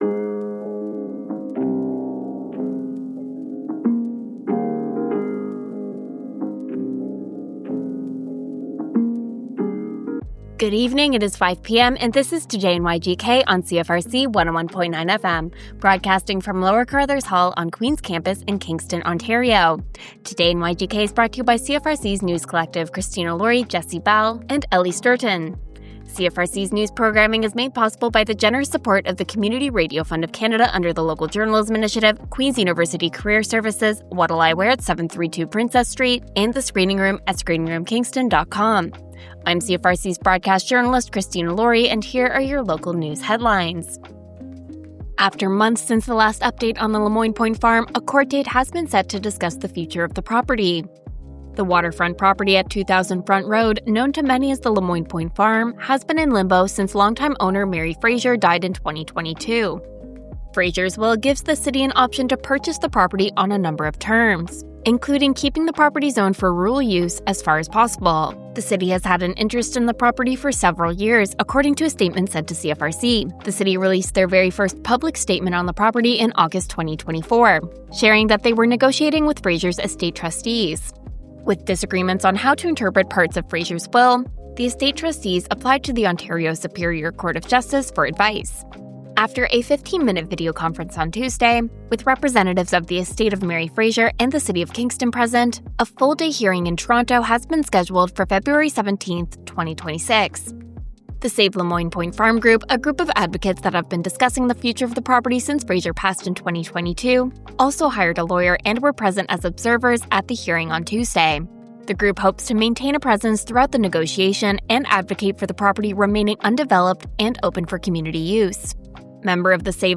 Local news with CFRC’s News Team.